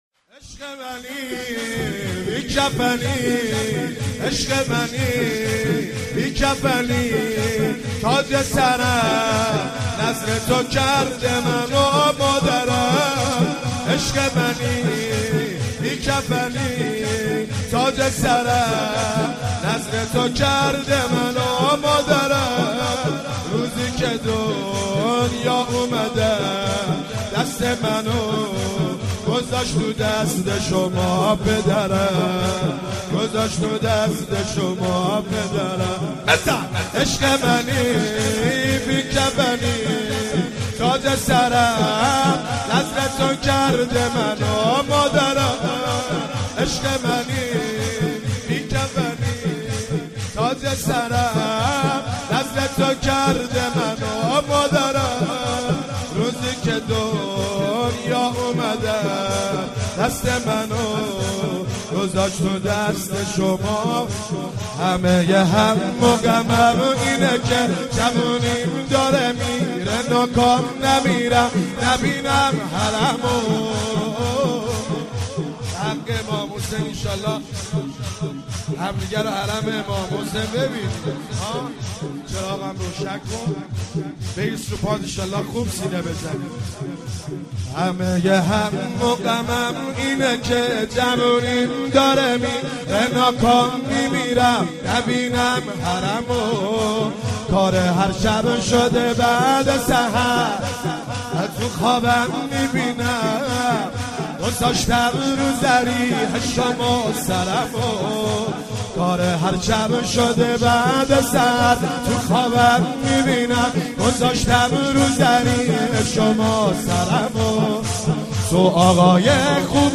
مراسم هفتگی هیئت مگتب المهدی (عج)
در حسینیه فاطمیه ارشاد برگزار شد